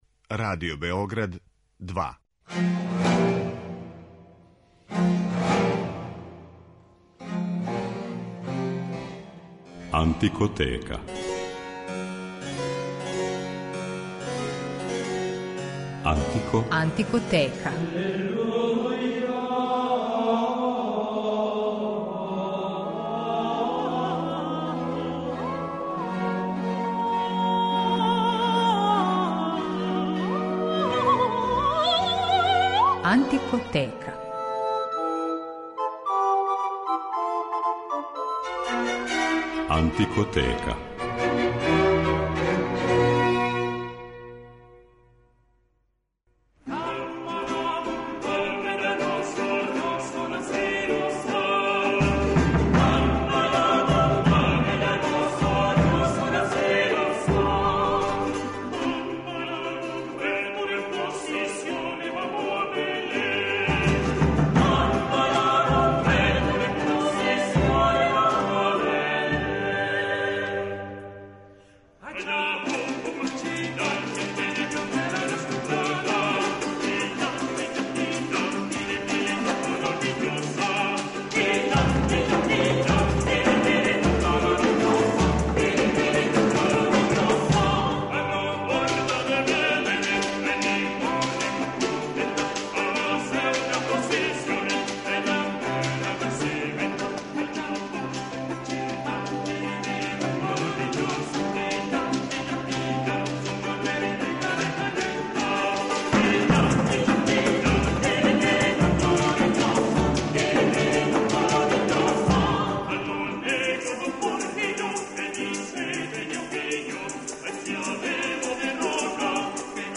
Латино-америчка музика 17. века
Она се стапала са музиком староседелаца (Индијанаца, али и досељеника из Африке, робова), те је добила необичан, препознатљив звук и каркатер проистекао из специфичног инструментаријума, и необичних ритмова.